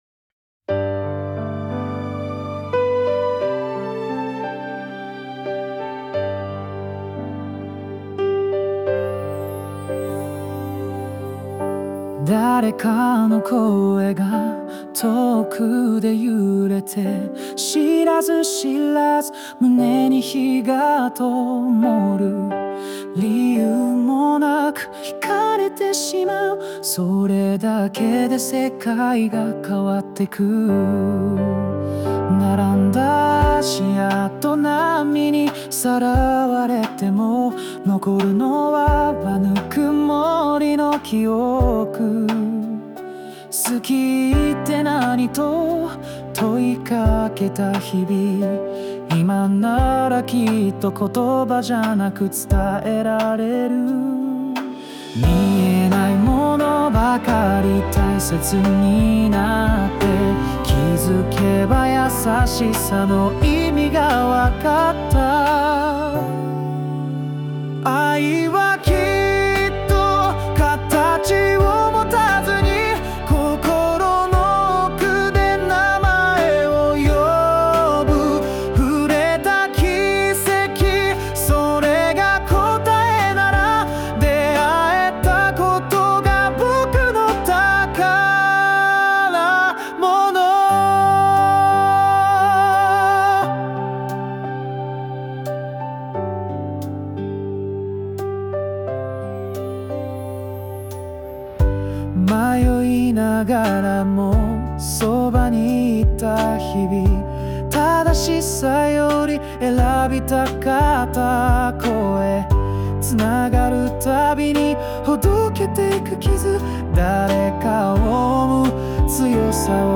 邦楽男性ボーカル著作権フリーBGM ボーカル
著作権フリーオリジナルBGMです。
男性ボーカル（邦楽・日本語）曲です。
「愛とは何か」「幸せとは何か」といった普遍的な問いに、あたたかく、静かに向き合う歌を目指しました